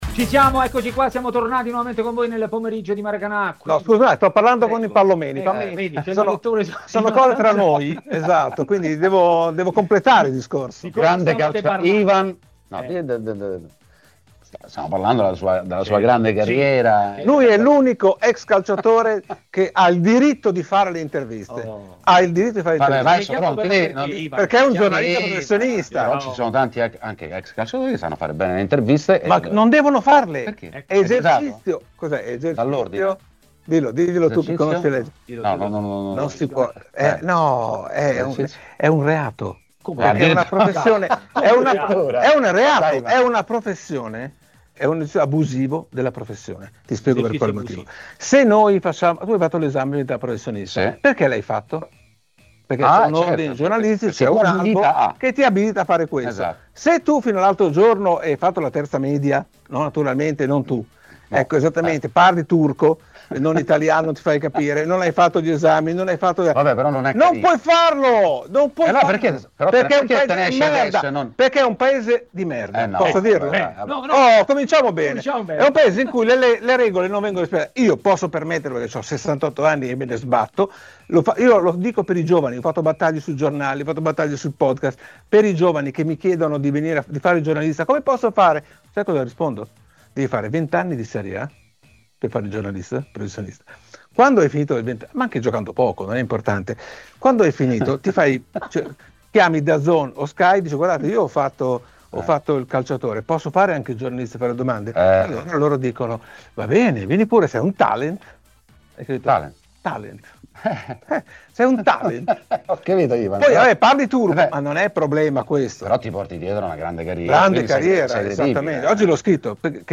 Ospite di Maracanà, nel pomeriggio di TMW Radio, è stato il direttore de Il Corriere dello Sport Ivan Zazzaroni.